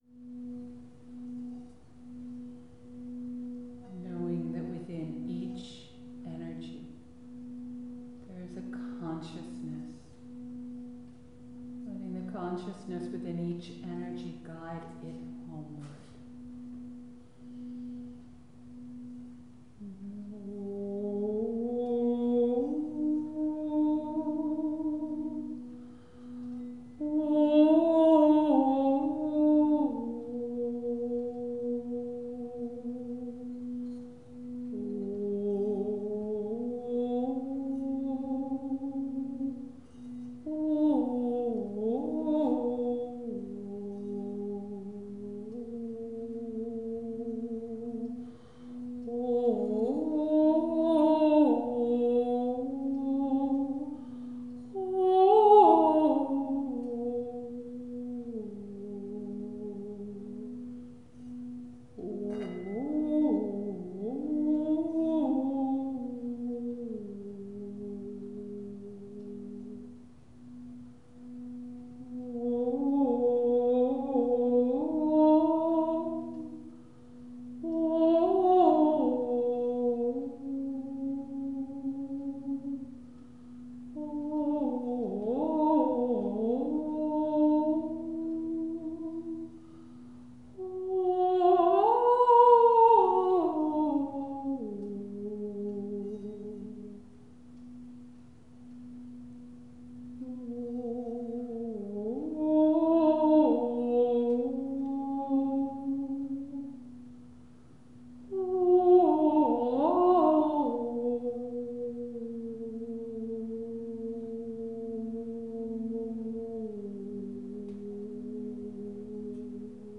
Includes video excerpts and a healing song